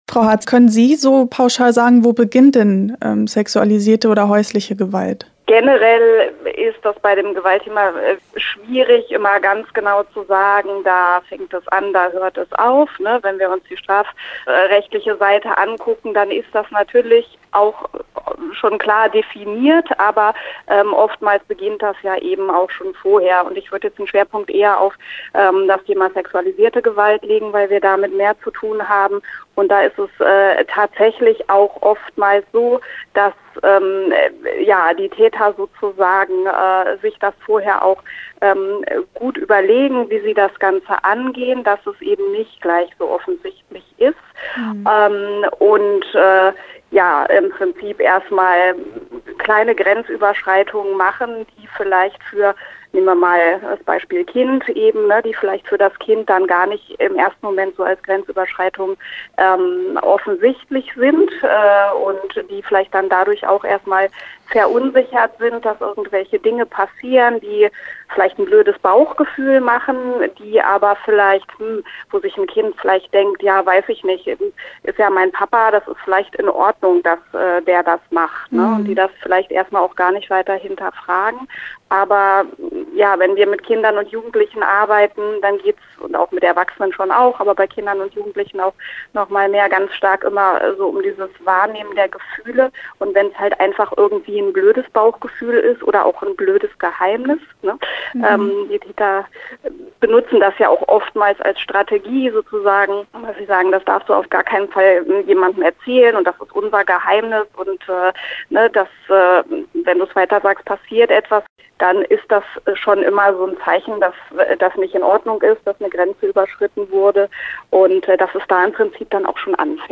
Interview-Hilfe-fuer-Gewaltopfer.mp3